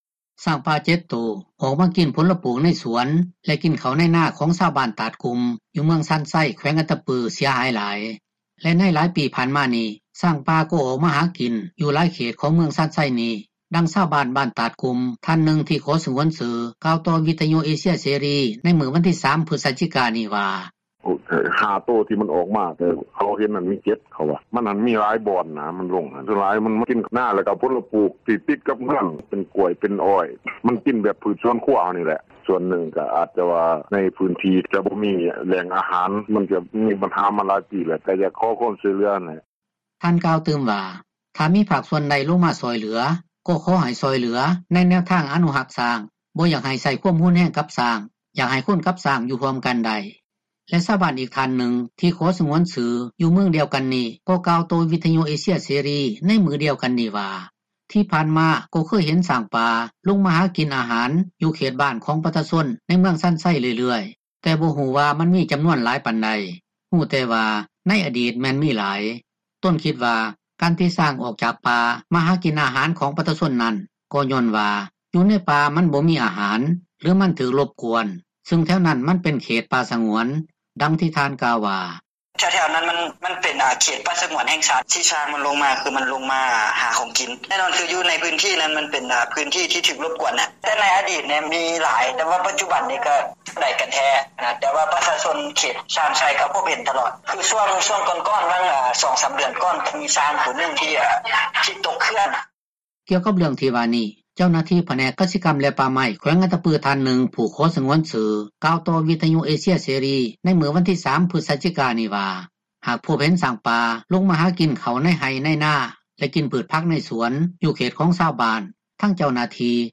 ຊ້າງປ່າ 7 ໂຕ ອອກມາກິນຜົລປູກໃນສວນ ແລະກິນເຂົ້າໃນນາຂອງຊາວບ້ານຕາດກຸ່ມ ຢູ່ເມືອງຊານໄຊ ແຂວງອັດຕະປື ເສັຍຫາຍຫລາຍ.